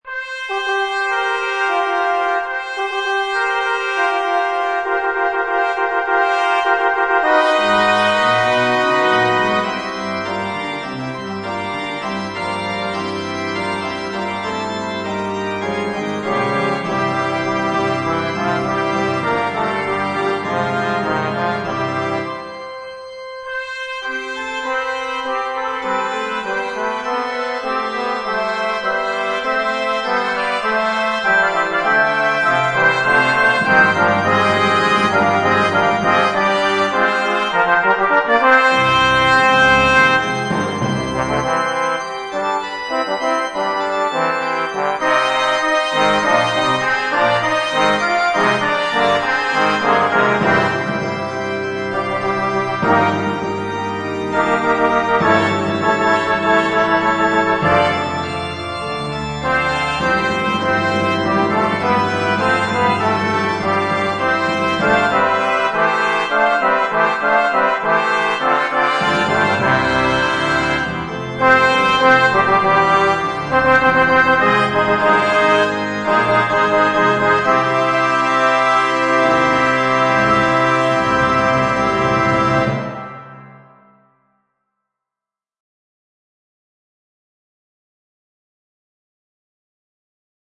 Composer: Organ Part